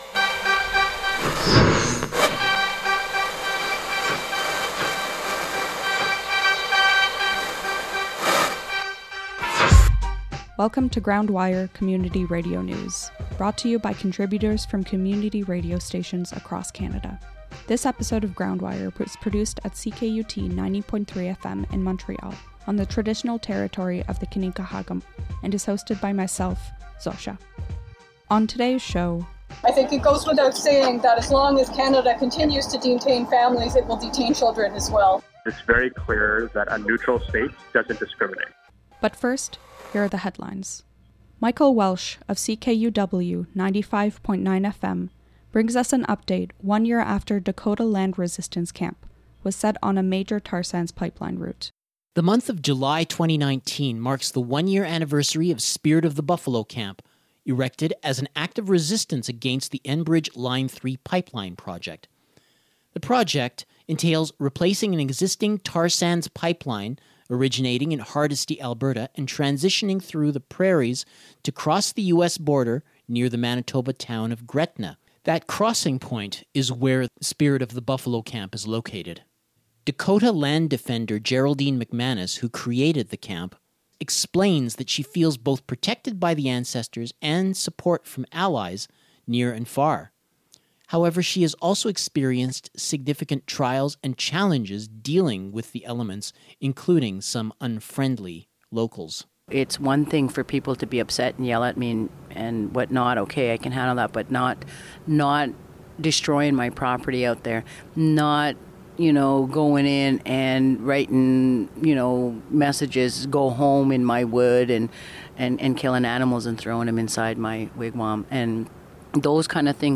Community radio news from coast to coast to coast.